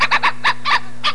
Arf Sound Effect
arf.mp3